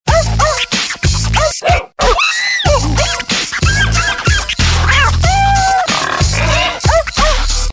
dog_ring.mp3